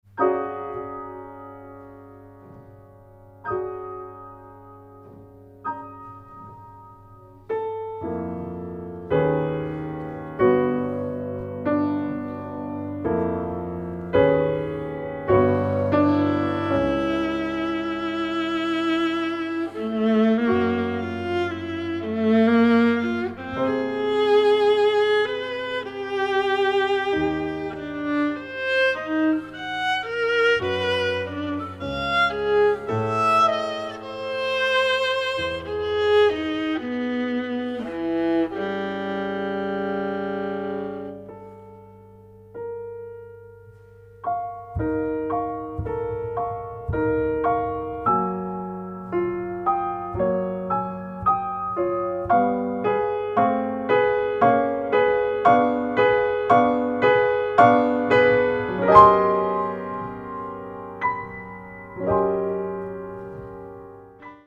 Sonata for Viola and Piano